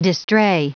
Prononciation du mot distrait en anglais (fichier audio)
Prononciation du mot : distrait